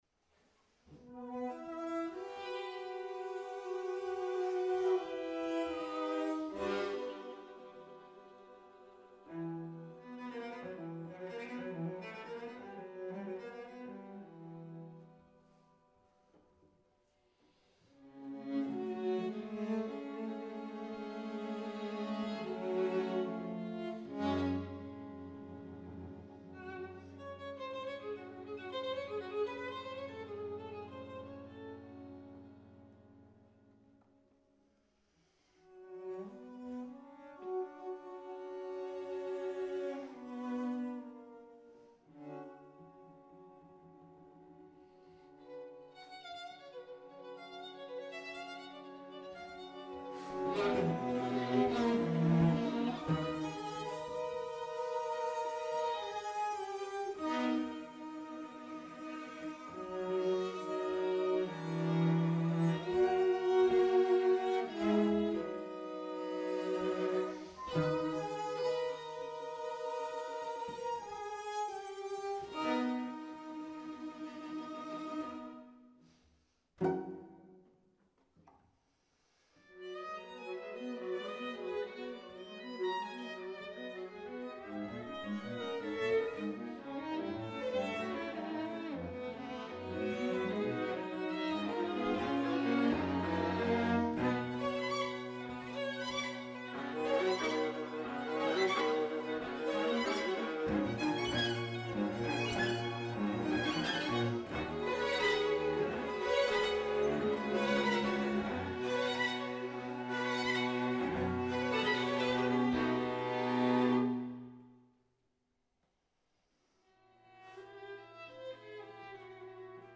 Venue: Bantry Library
Instrumentation: 2vn, va, vc Instrumentation Category:String Quartet
violins
viola
cello